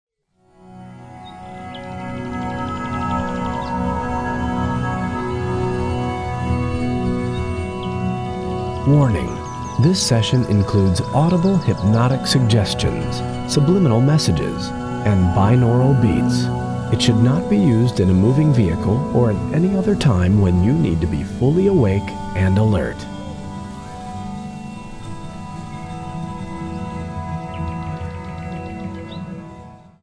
subliminal messages,
healing music,
meditation music,
new age music,
relaxation music,
binaural beats,